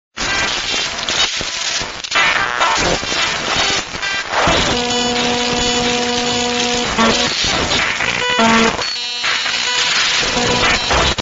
Nokia Sms RingTones